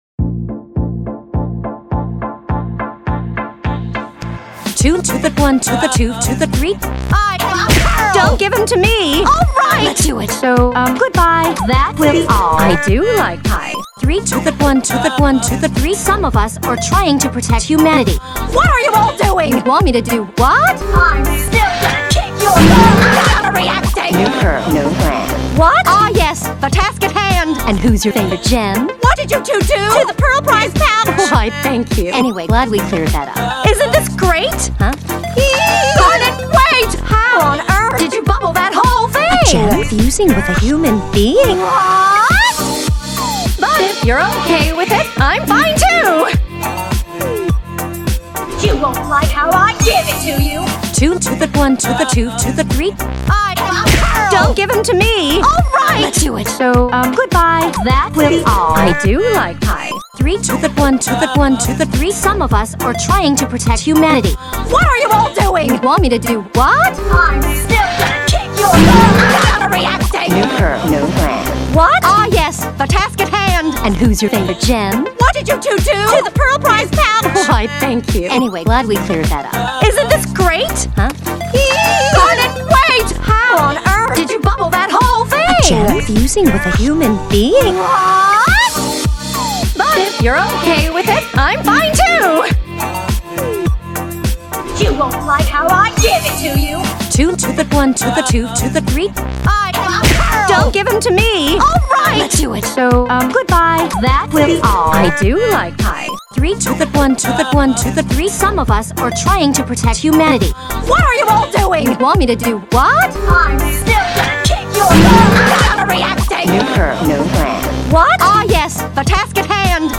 5 minute looping extended version of today’s video–hope you enjoy!!